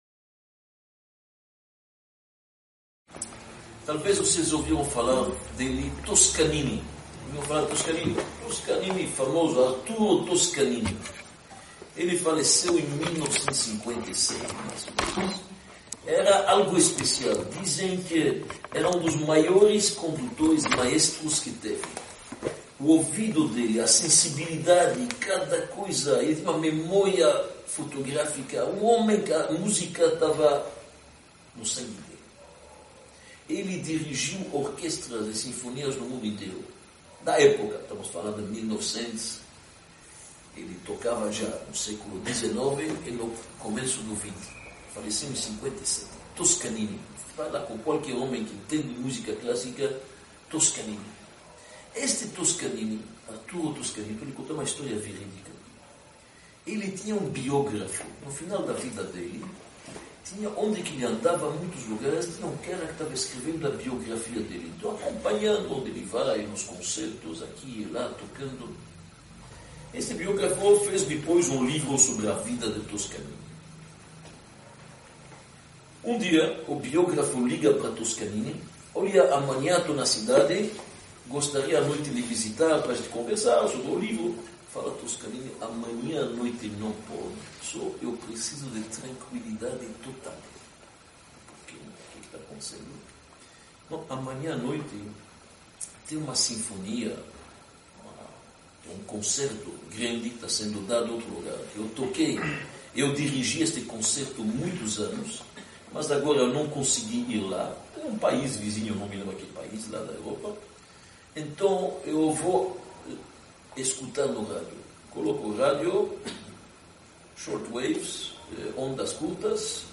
Palestra-Parte-3_-Cada-filho-é-uma-nota-indispensável-para-a-sinfonia-familiar-1.mp3